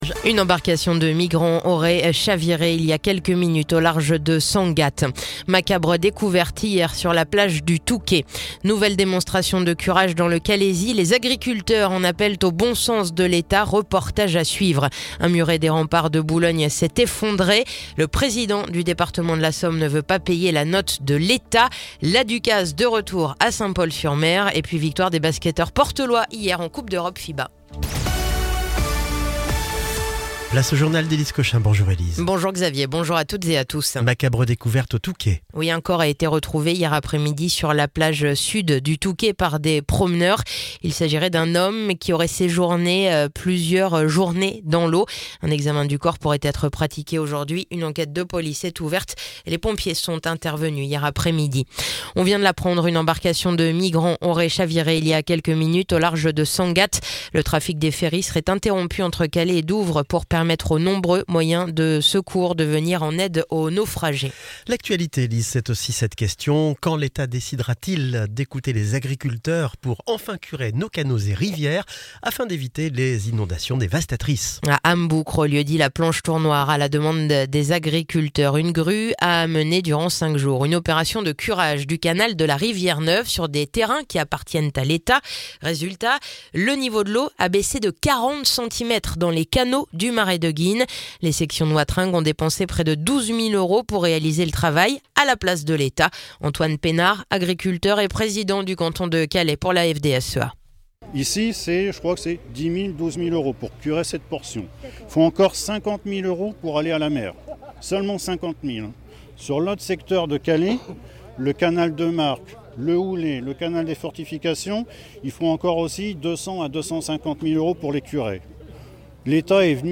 Le journal du mercredi 23 octobre